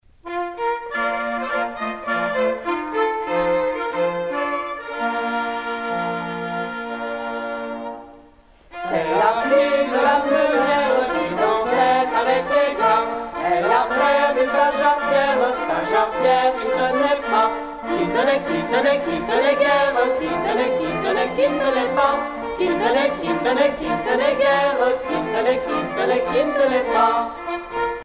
galop
Chants brefs - A danser
Pièce musicale éditée